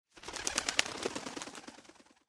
Sound Effects
Birds Flying Away